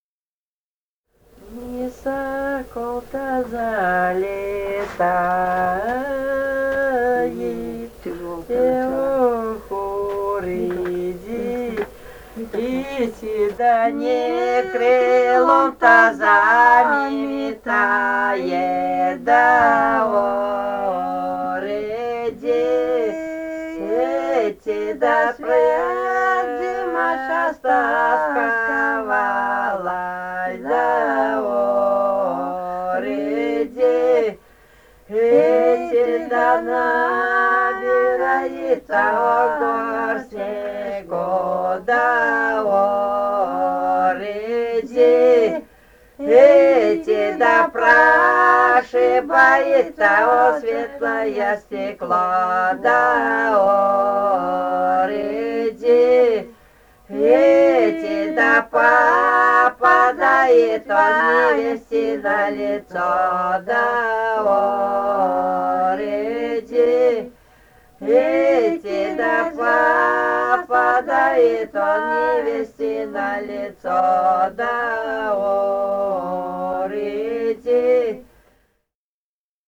Бурятия, с. Петропавловка Джидинского района, 1966 г. И0903-05